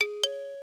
ding end pitch-change short xylophone sound effect free sound royalty free Sound Effects